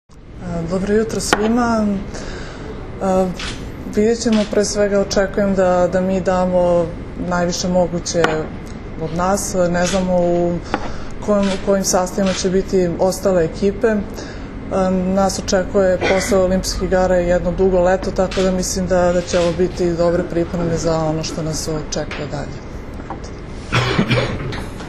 Na aerodromu “Nikola Tesla” jutros je održana konferencdija za novinare, na kojoj su se predstavnicima medija obratili Zoran Terzić, prvi trener seniorki Srbije, i Jelena Nikolić, kapiten Srbije.
IZJAVA JELENE NIKOLIĆ 1